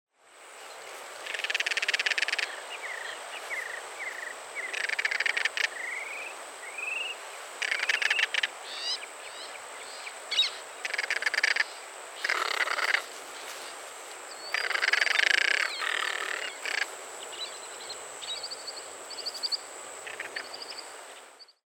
The Crested Caracara, Caracara cheriway, is the Beau Brummell of birds, a dandy with a certain air, sometimes called the Mexican Eagle.
The namecaracara” is supposedly from the Guarani Indian traro-traro, after the rattling vocalization that they produce when upset:
And no, I didn’t think that that was spaghetti!😬 Their vocalization almost sounded like a bird burp.